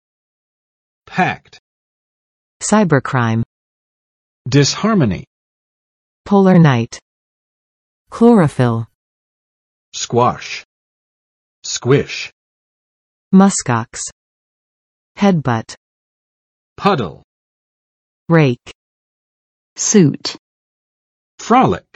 [pækt] adj. 塞得滿滿的，擁擠的